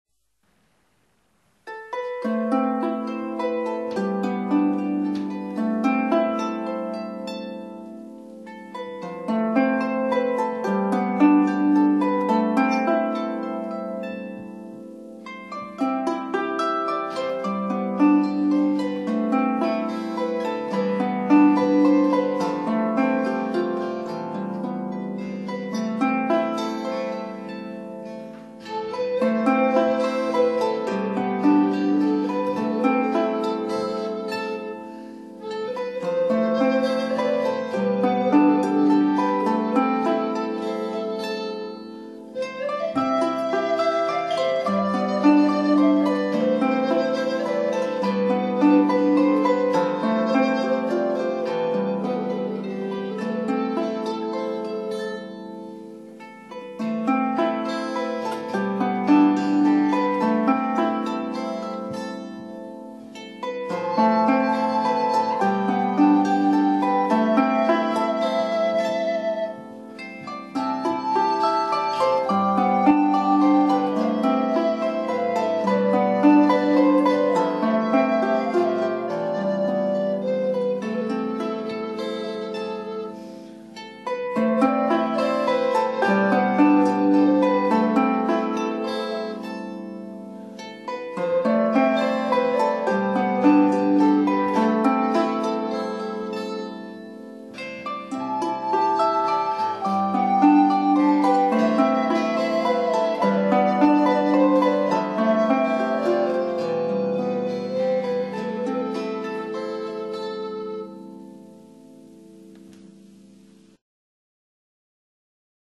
Recorded at Flying Fiddle Studio
Guitar